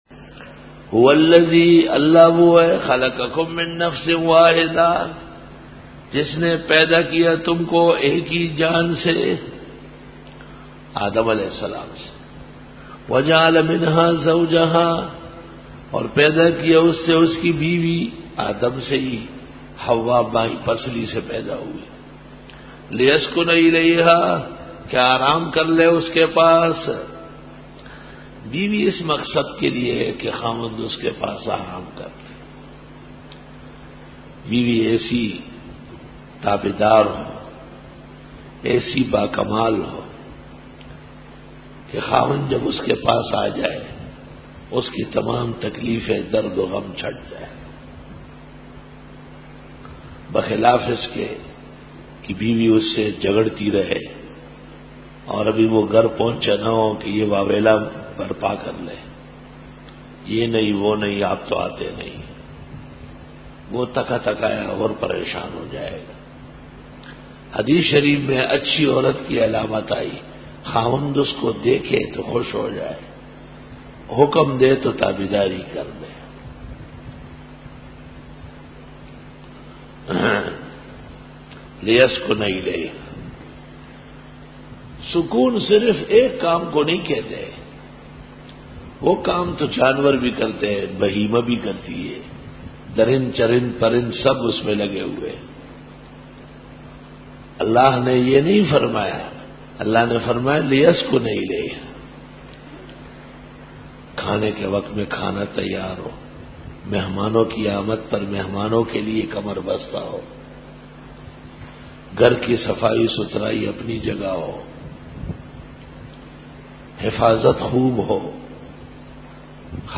Dora-e-Tafseer 2007